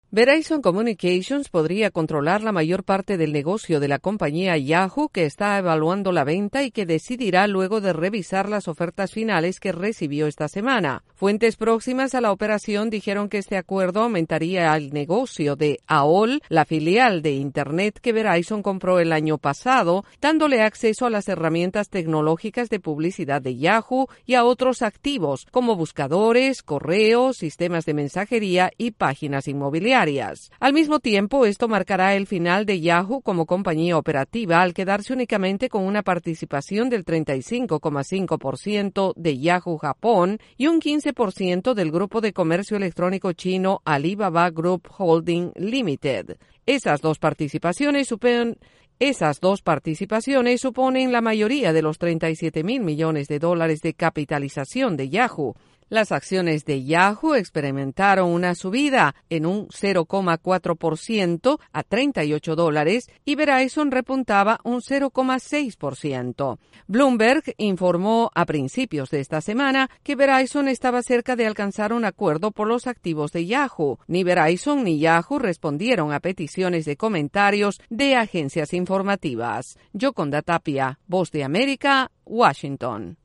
Yahoo está a punto de decidir la venta de una gran parte de su negocio y podría decidir en brreve. Desde la Voz de América en Washington DC informa